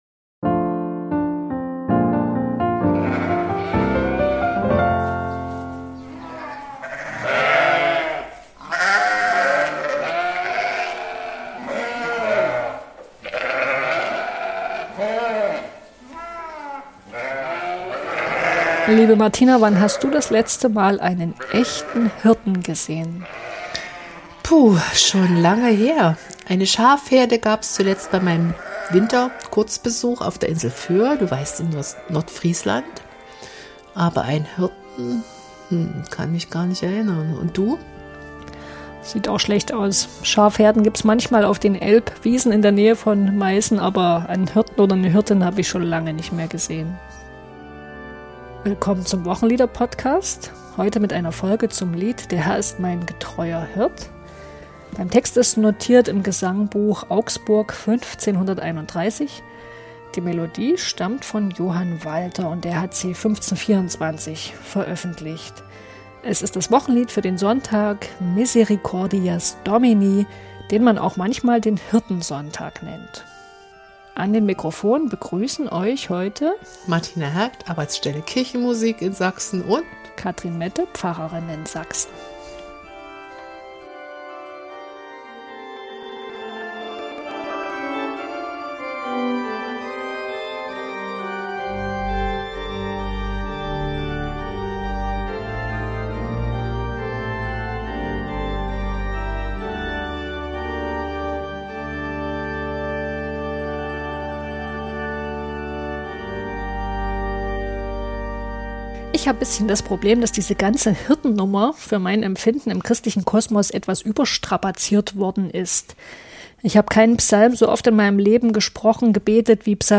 Allerdings geht das mit den Kabelkopfhörern, die wir während der Aufnahme immer tragen, schlecht.